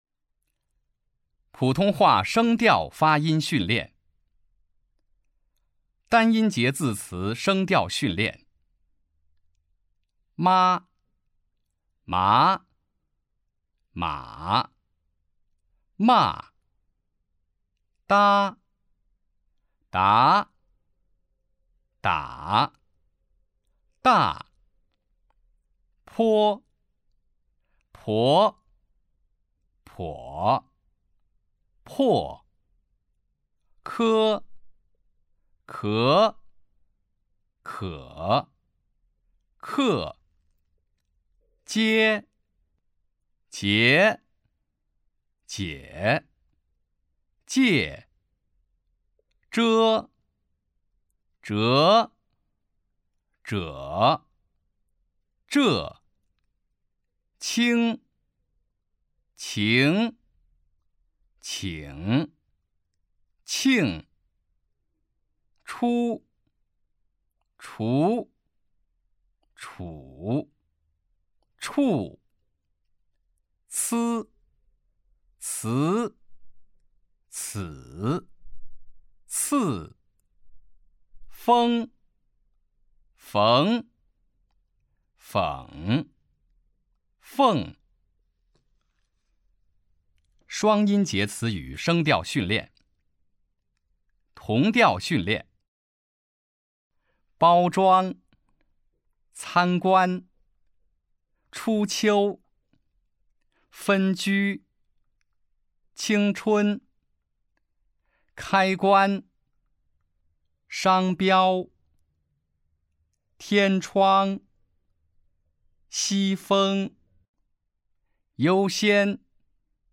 005普通话声调发音训练.mp3